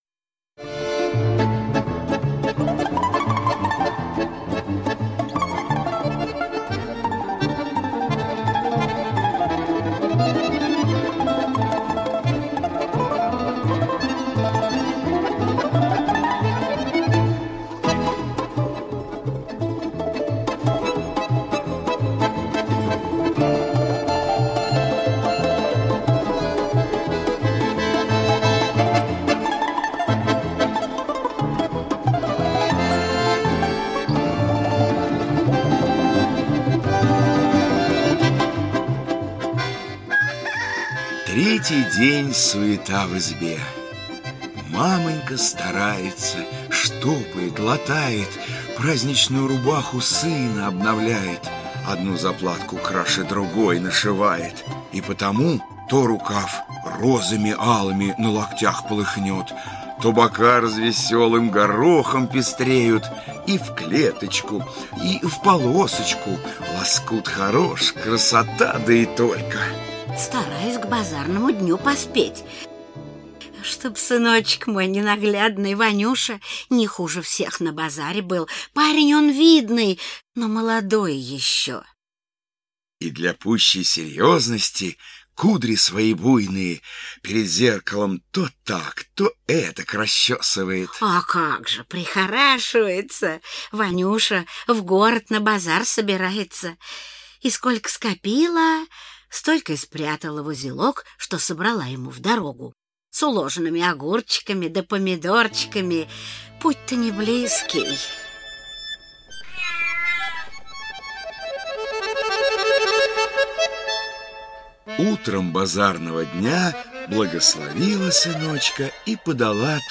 Аудиокнига Белая верблюдица | Библиотека аудиокниг